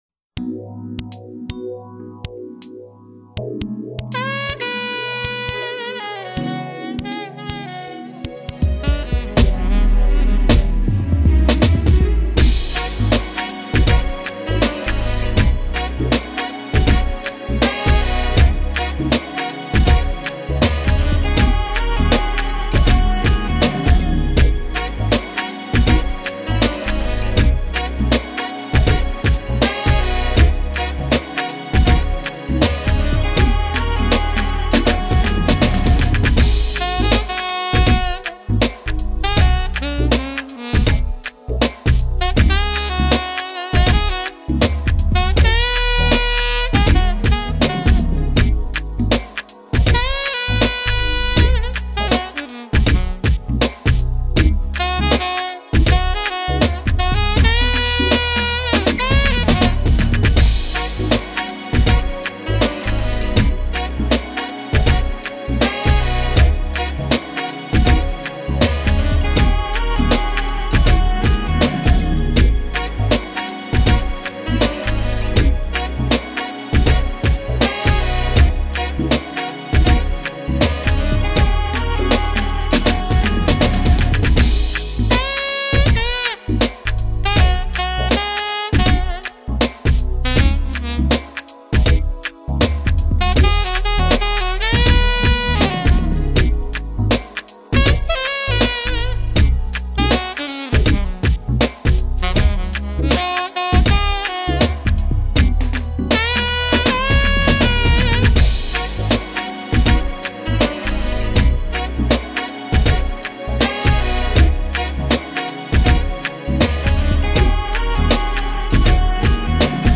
Smooth Jazz Network ™